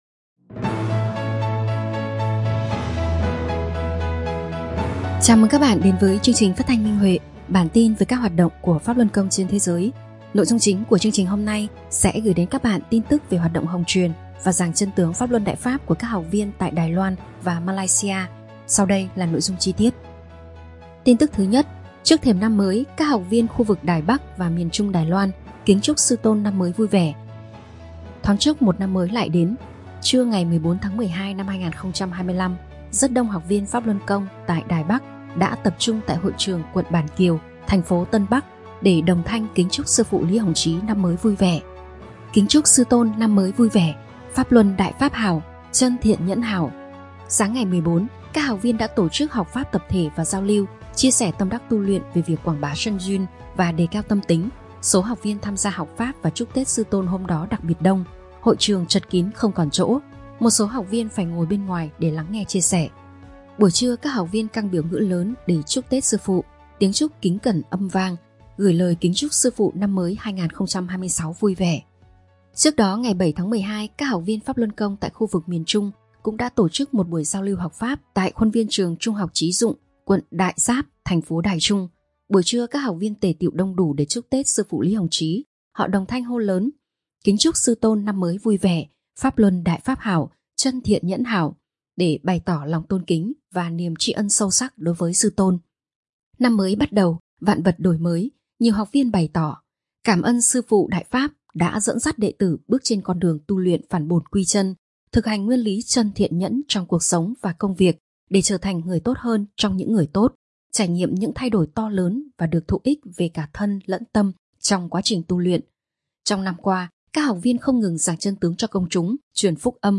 Chương trình phát thanh số 420: Tin tức Pháp Luân Đại Pháp trên thế giới – Ngày 26/12/2025